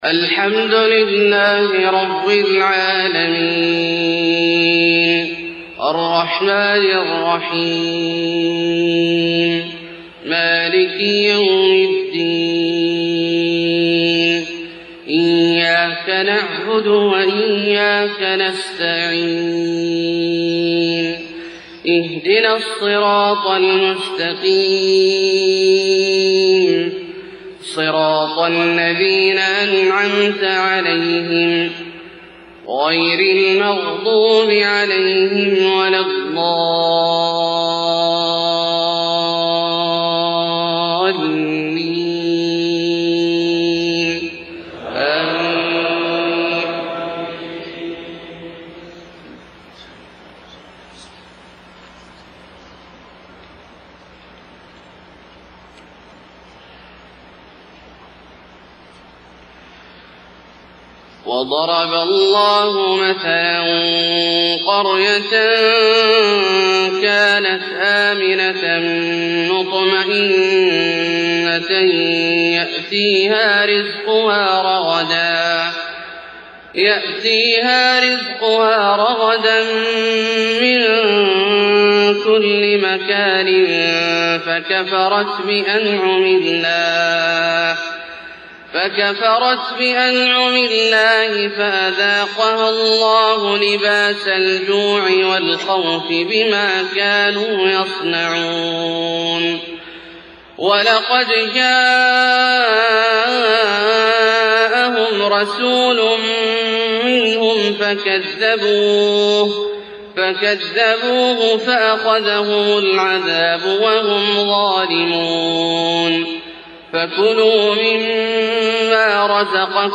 فجر 8-7-1430 من سورة النحل {112-128} > ١٤٣٠ هـ > الفروض - تلاوات عبدالله الجهني